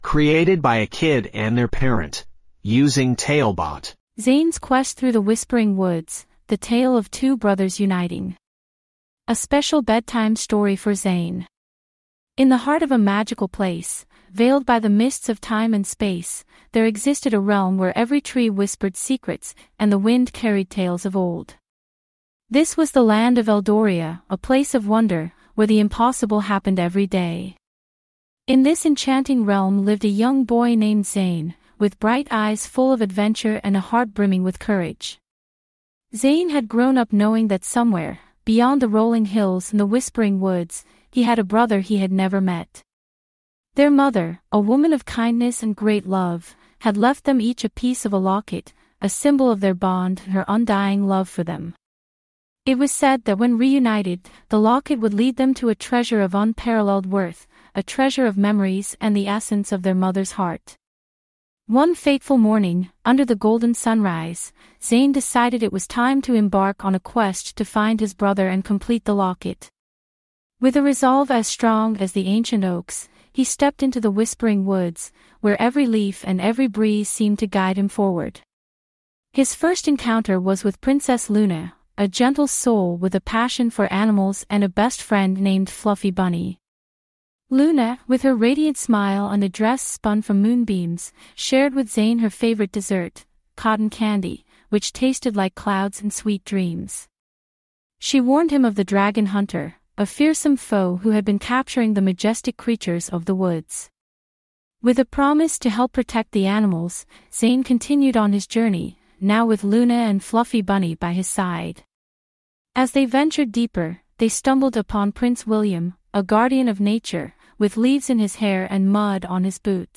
5 Minute Bedtime Stories
TaleBot AI Storyteller